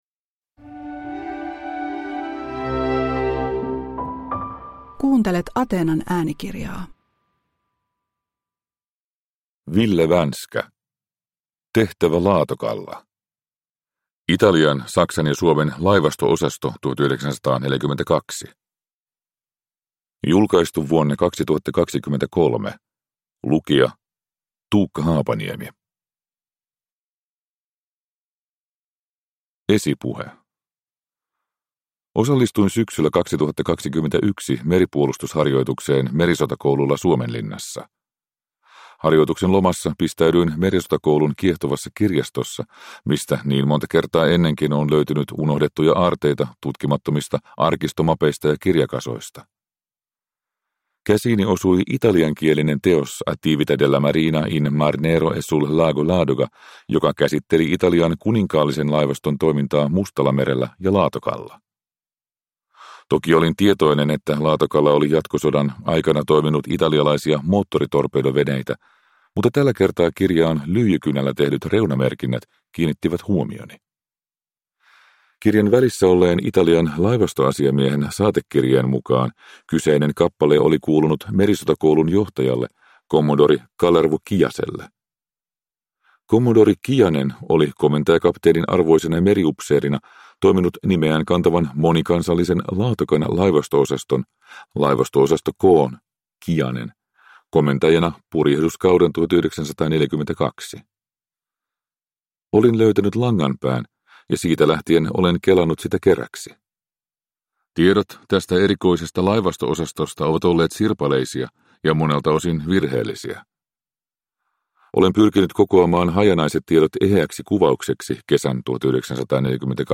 Tehtävä Laatokalla – Ljudbok – Laddas ner